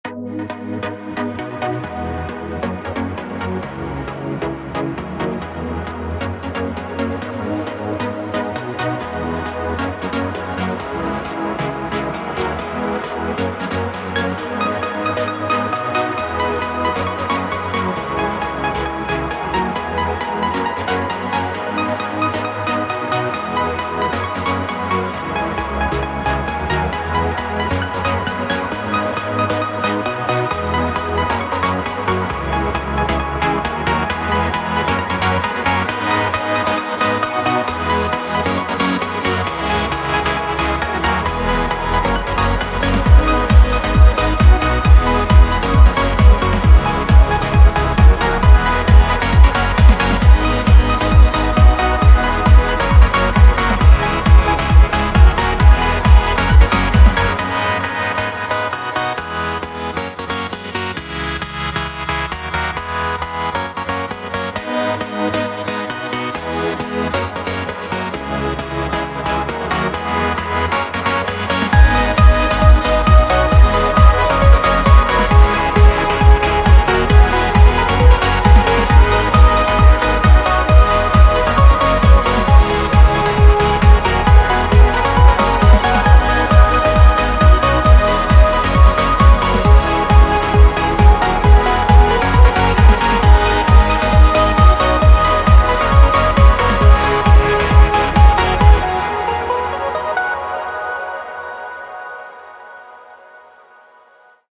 New example MIDI Tracker track - playing it LIVE in real-time:
MP3 Music file (1.6M) - Live recording MP3